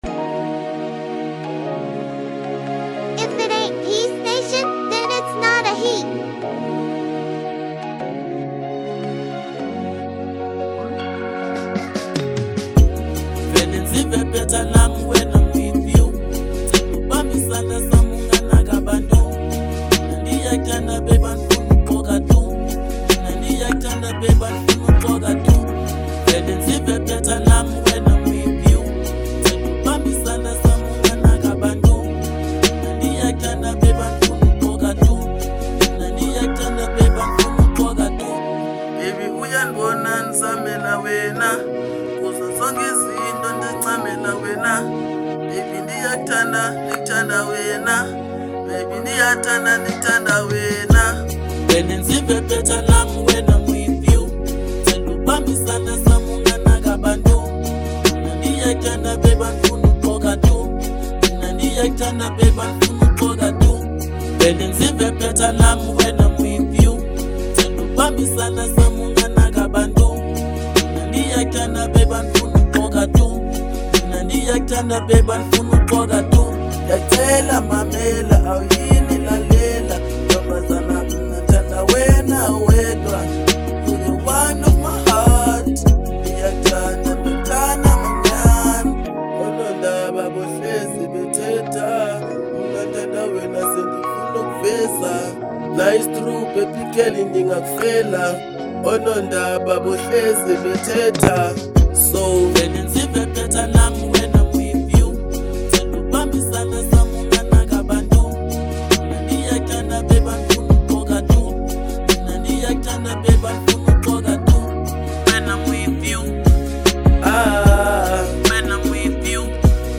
02:07 Genre : Afro Pop Size